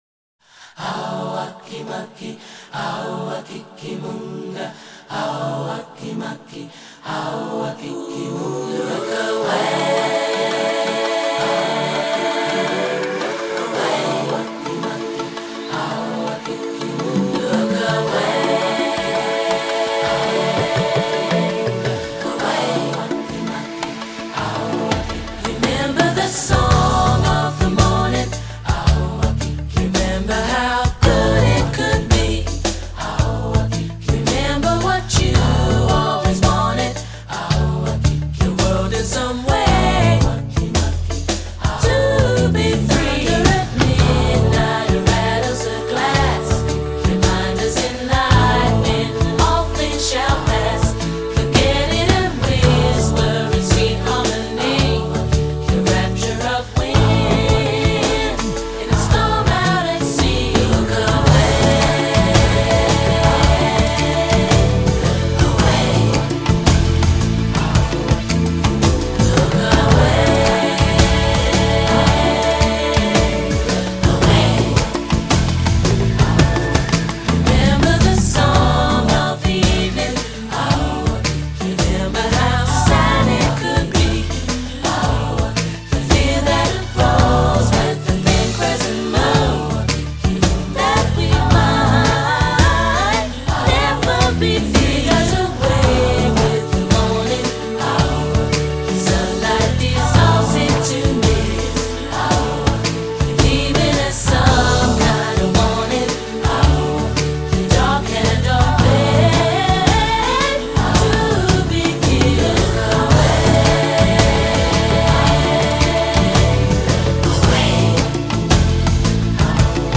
Percussionists
lilting vocals
British a capella group